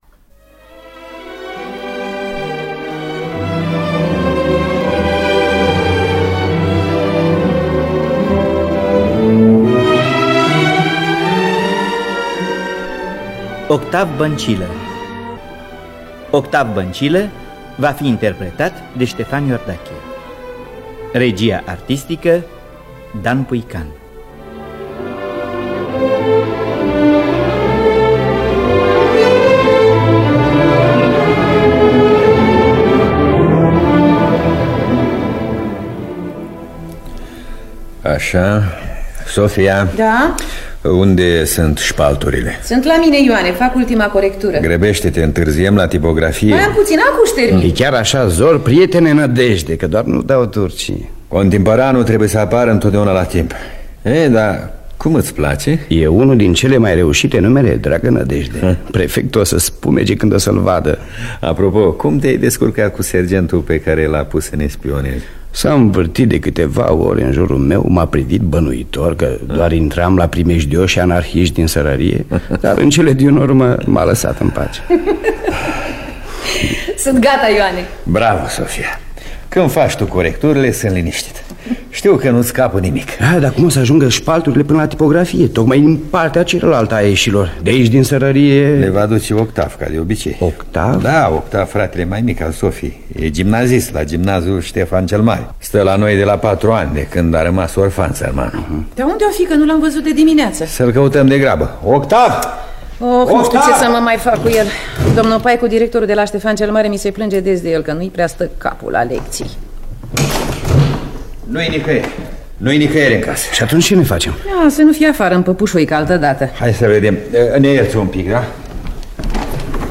Scenariu radiofonic de Radu Stern.